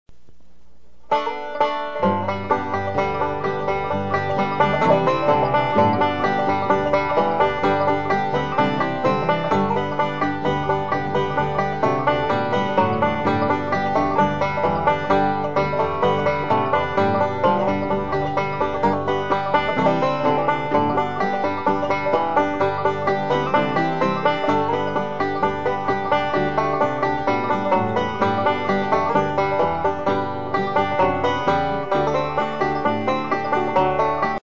5-String Banjo .mp3 Samples
5-String Banjo Samples - Basic Level